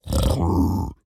Minecraft Version Minecraft Version 1.21.5 Latest Release | Latest Snapshot 1.21.5 / assets / minecraft / sounds / mob / piglin_brute / idle9.ogg Compare With Compare With Latest Release | Latest Snapshot